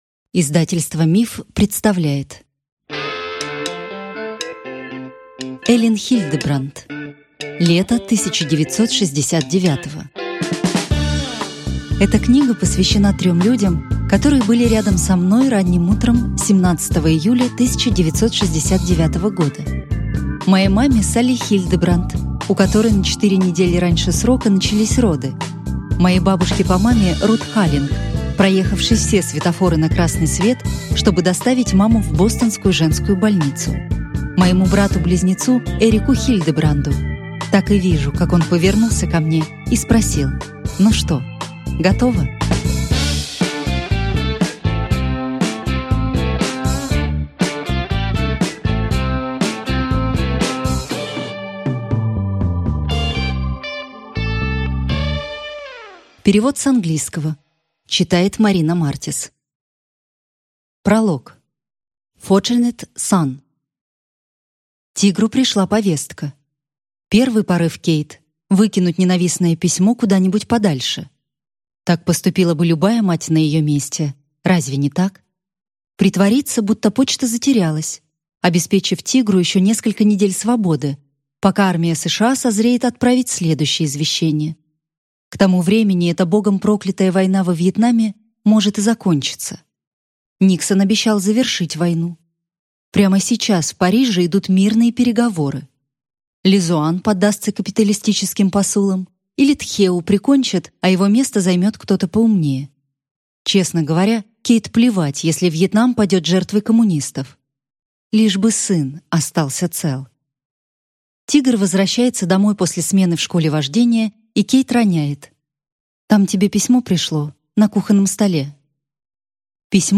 Аудиокнига Лето 1969 | Библиотека аудиокниг